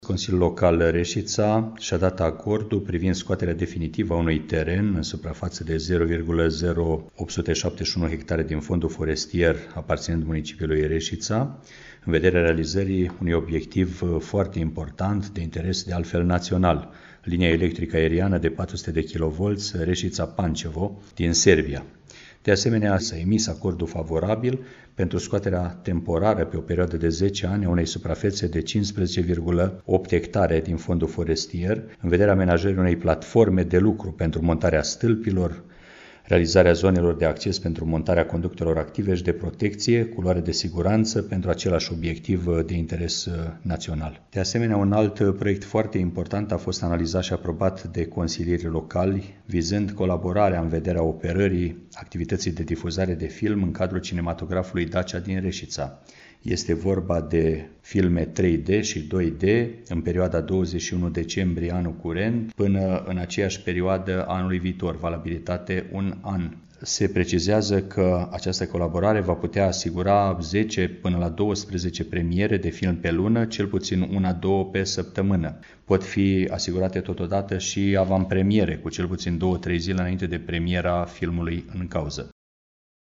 Ascultaţi declaraţia primarului Mihai Stepanescu